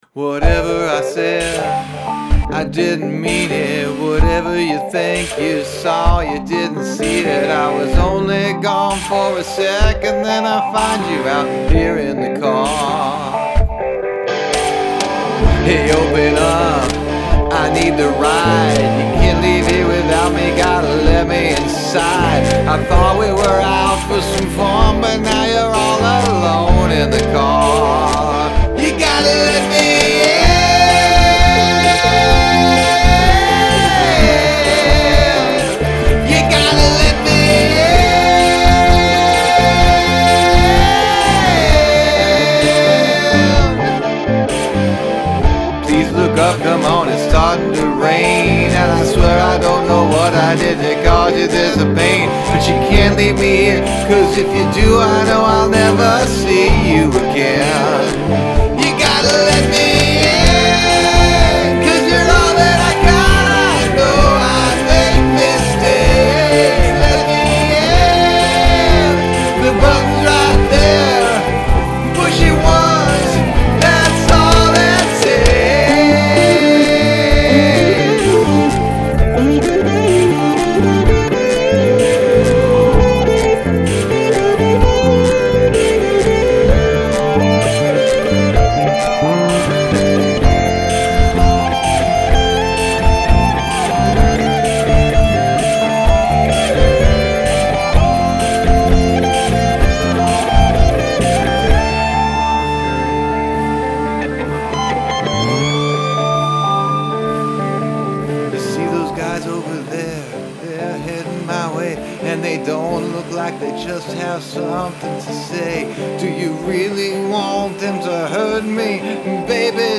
Uncommon Percussion